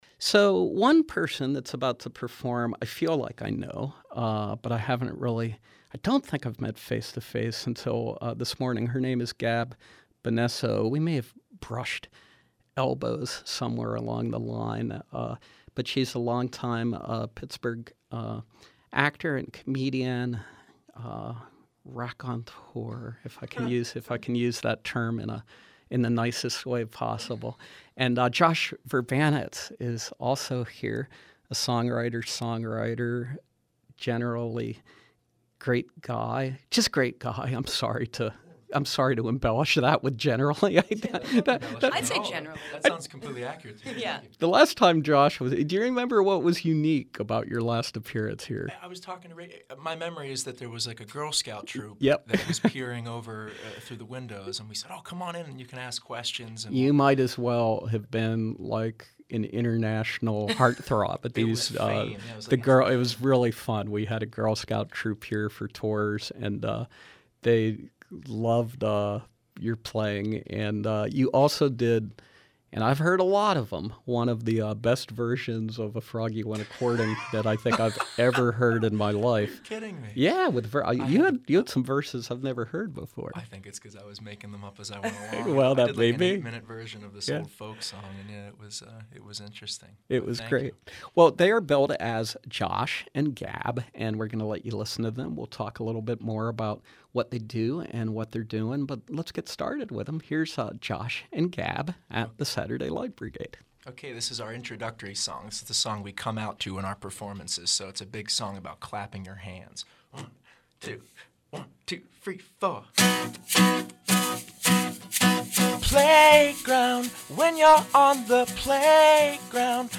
performing their upbeat anti-bullying anthems